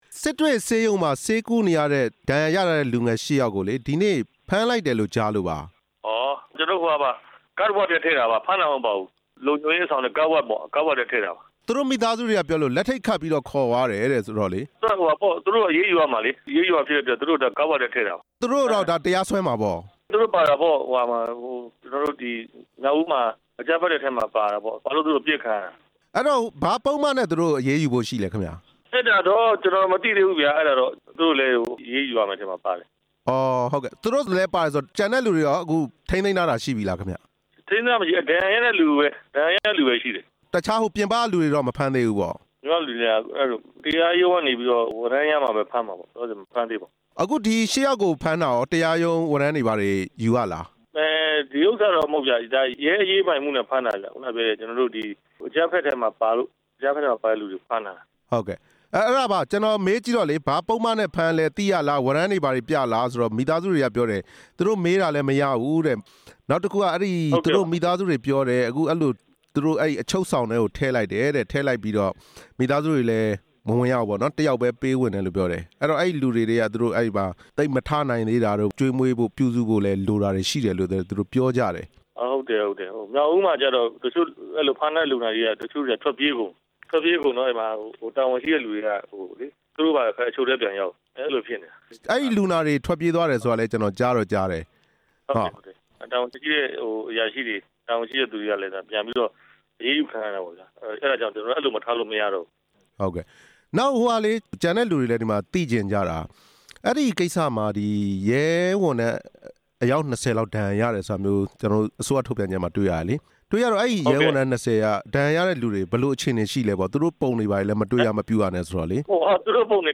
ဒဏ်ရာရ လူငယ် ၈ ဦးကို အရေးယူတဲ့အကြောင်း မေးမြန်းချက်